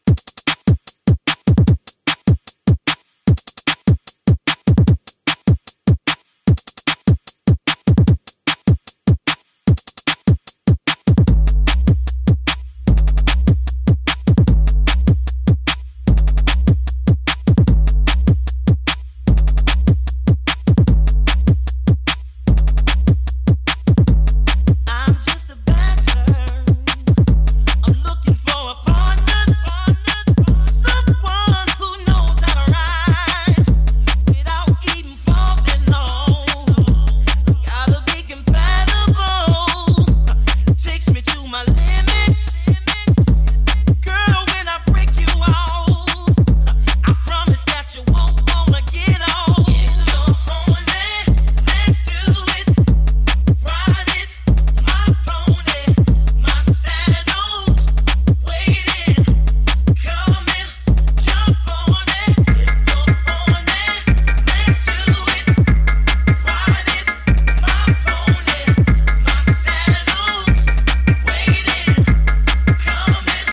Real deep and emotional booty grind music.